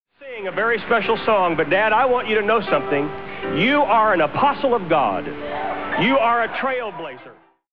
The radio used to receive these stations is the Grundig S350.